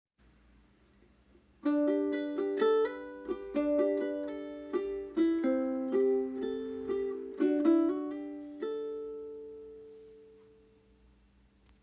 Die Cascha Carbon Ukulele überzeugt durch ihre Einzigartigkeit und den herausragenden Klang.
Die präzisen Stimmmechaniken und langlebigen, stimmfesten Fluorcarbon-Saiten machen diese Ukulele zu einem sehr stimmstabilen Instrument mit heller und klarer Klangfarbe.